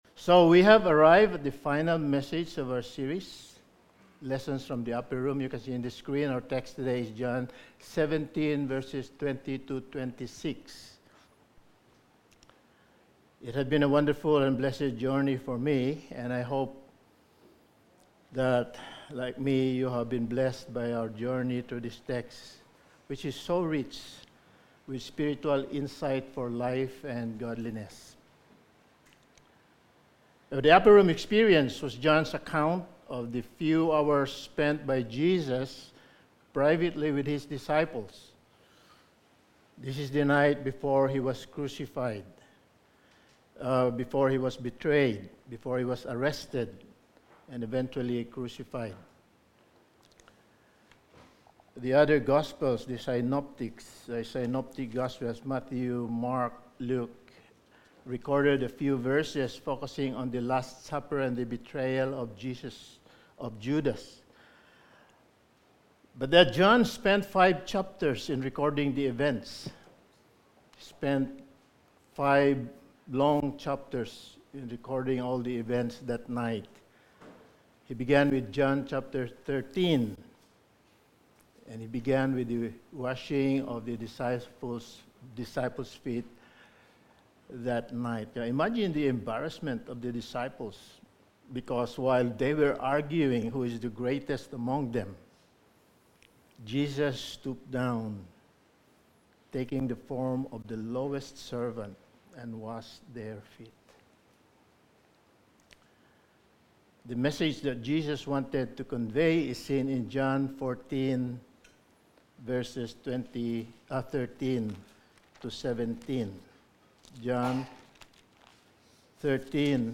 Lessons From the Upper Room Series – Sermon 14: He Prays For Us
Passage: John 17:20-26 Service Type: Sunday Morning